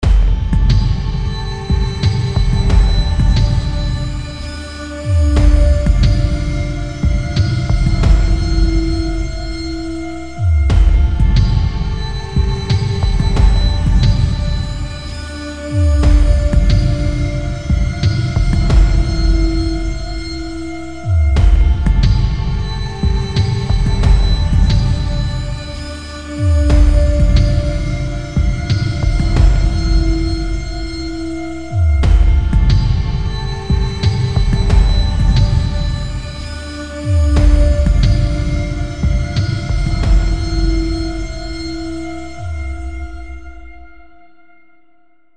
красивые
без слов